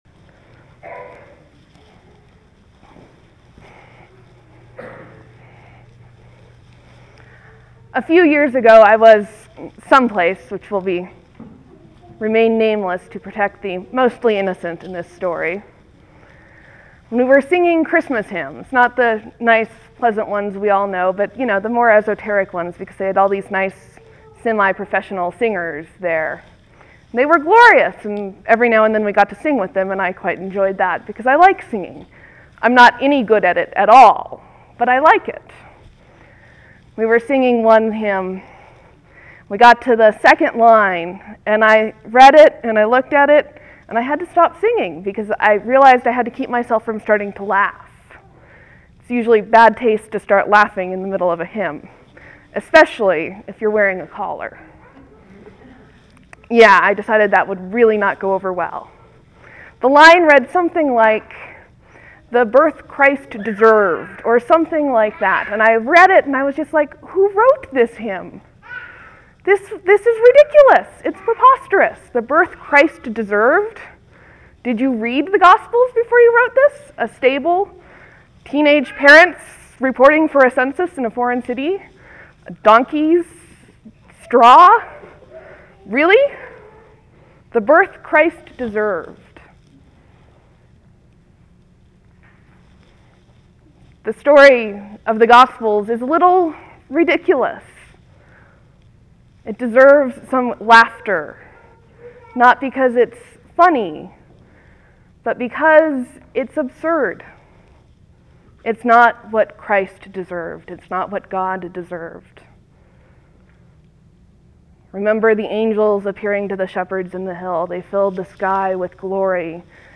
(There will be a few seconds of silence before the sermon starts. Thanks for your patience.)